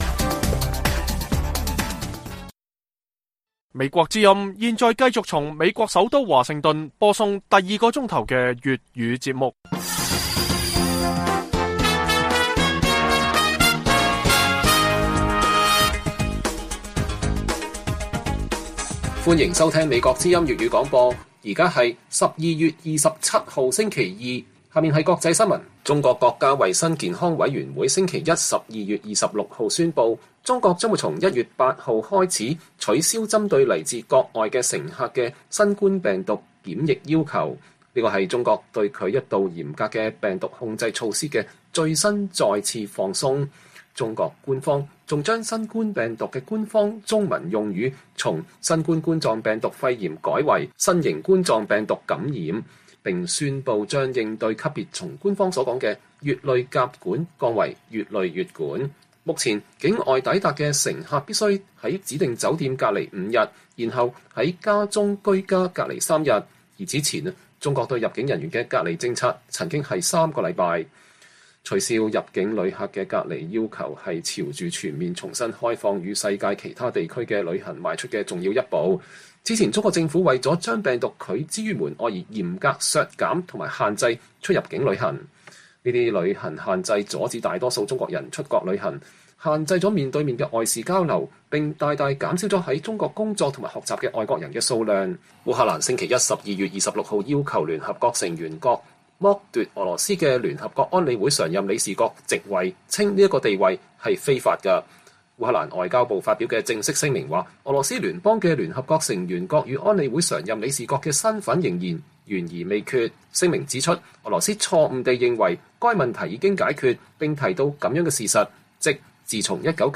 粵語新聞 晚上10-11點 : 澳外長訪中破冰 專家:工黨政府懂得管理澳中分歧